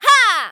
YX长声2.wav 0:00.00 0:00.53 YX长声2.wav WAV · 45 KB · 單聲道 (1ch) 下载文件 本站所有音效均采用 CC0 授权 ，可免费用于商业与个人项目，无需署名。
人声采集素材